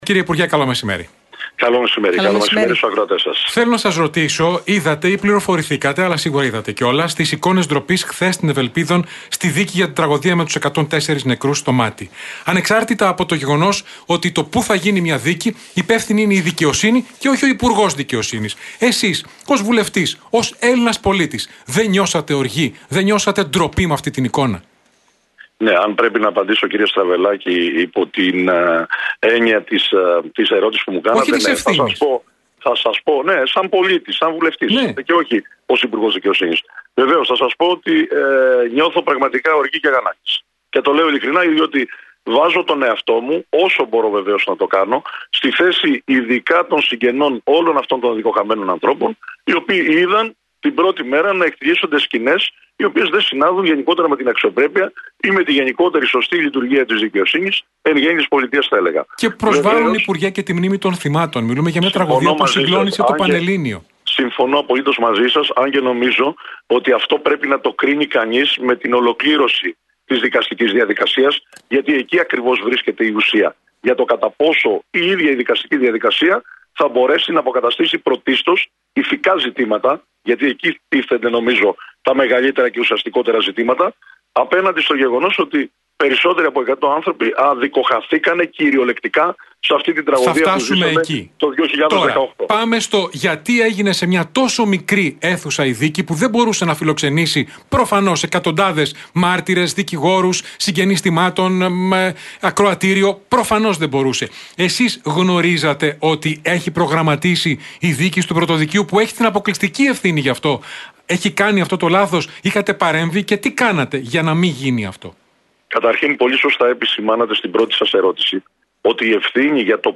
Ακούστε όλα όσα είπε ο Κώστας Τσιάρας στον Realfm 97,8 σχετικά με τη δίκη για το Μάτι